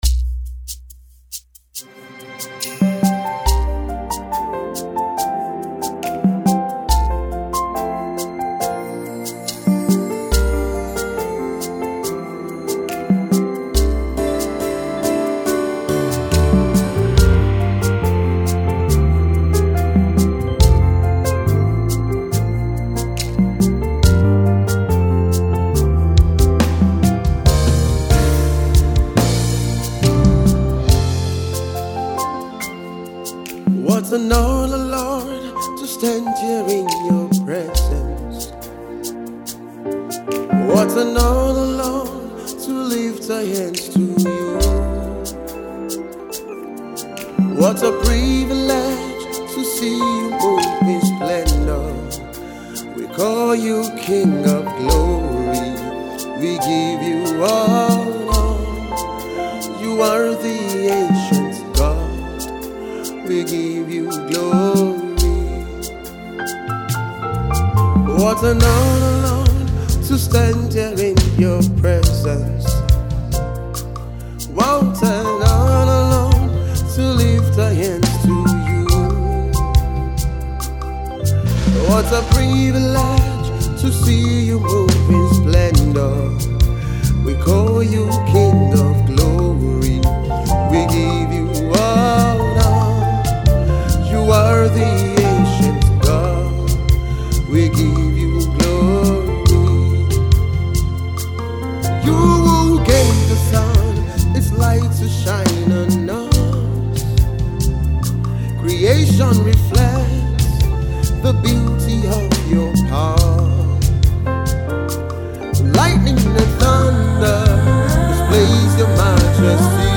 a song of adoration